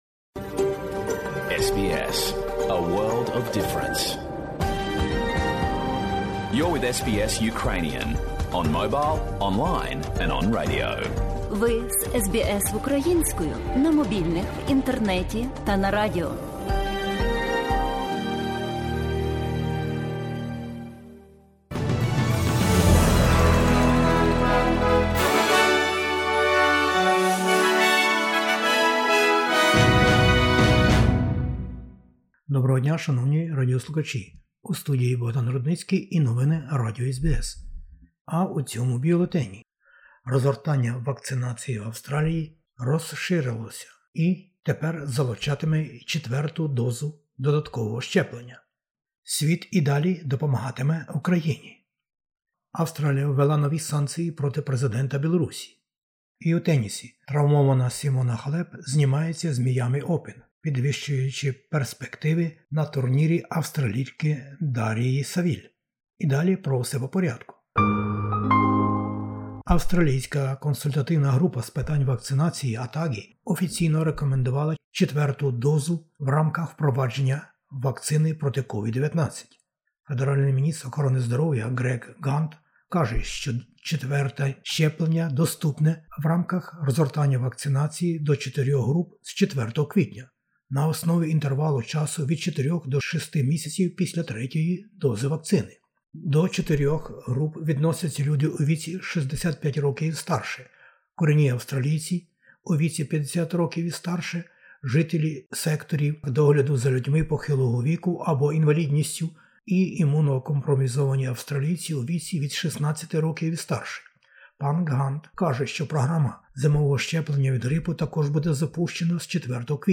SBS новини українською. Країни світу про підтримку України. За програмою вакцинації Австралії - четверте щеплення для певної катеґорії людей з 4 квітня.